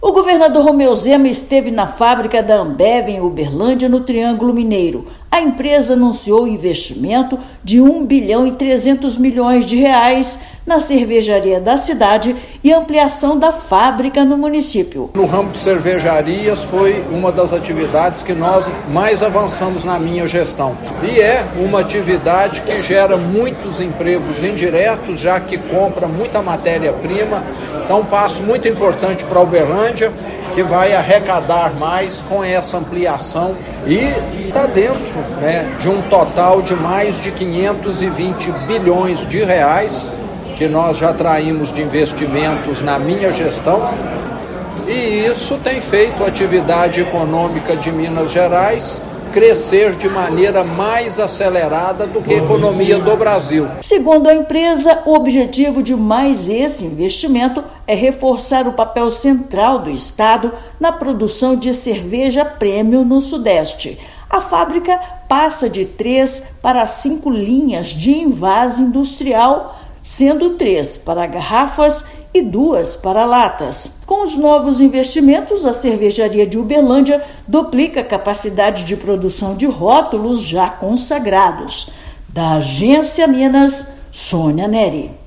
Com os novos investimentos, a cervejaria duplica a capacidade de produção de rótulos consagrados do segmento premium e core plus na cidade. Ouça matéria de rádio.